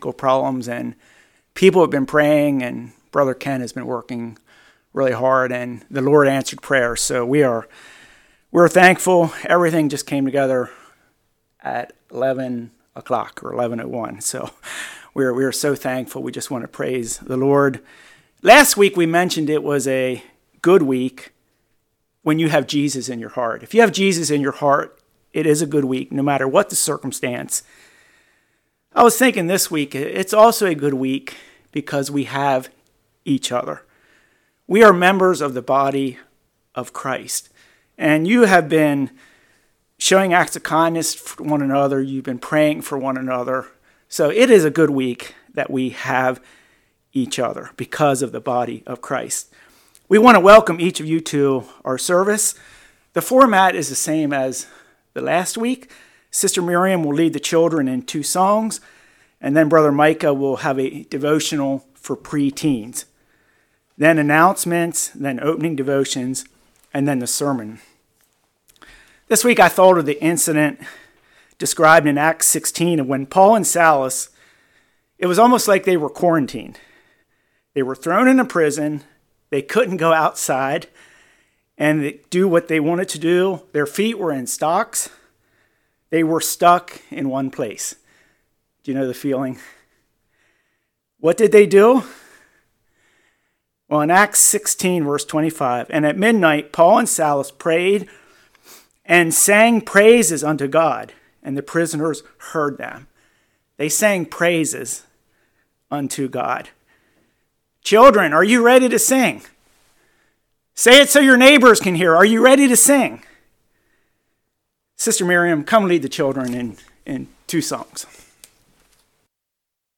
1 Peter 3:14-16 Service Type: Morning Why this topic at such a time?